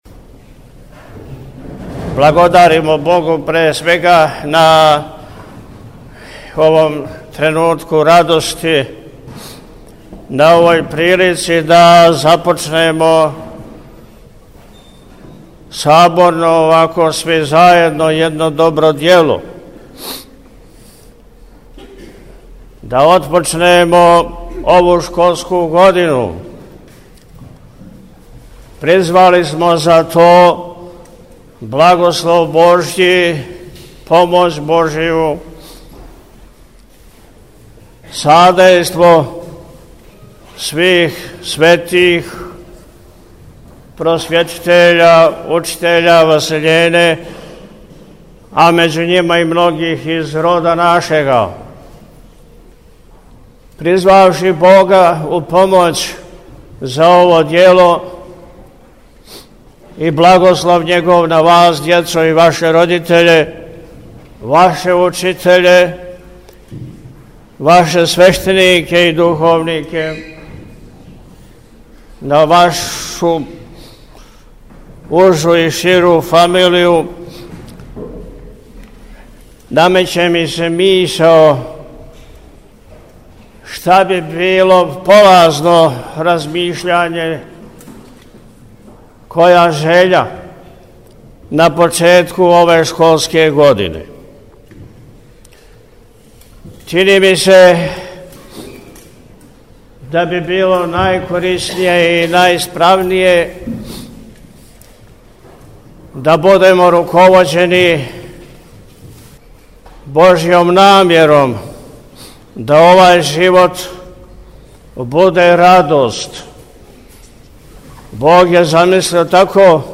Његово Преосвештенство Епископ милешевски г. Атанасије служио је 6. септембра 2023. године, након вечерњег богослужења, у храму Светог Василија Острошког у Пријепољу, Молебан за благословен почетак нове школске године.
Prijepolje-Moleban-Skola.mp3